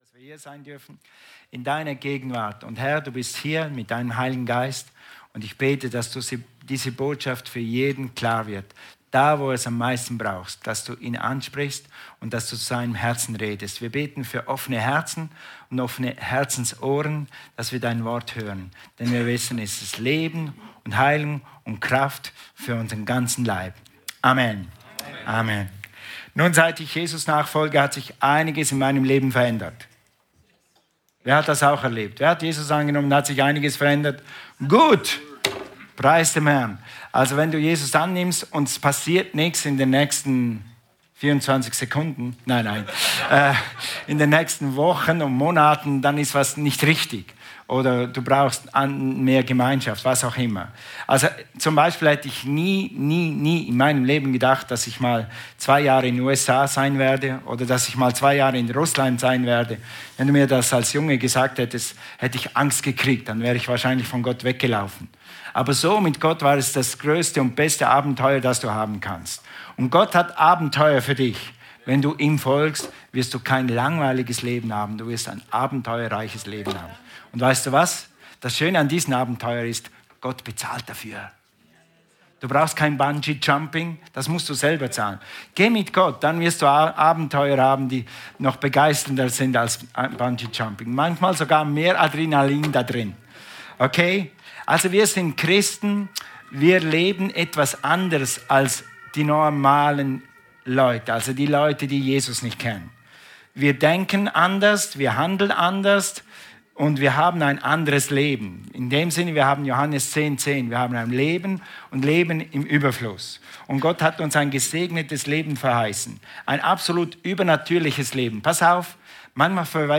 Der Helfer - Die Kraftquelle in dir Teil 1 ~ Predigten vom Sonntag Podcast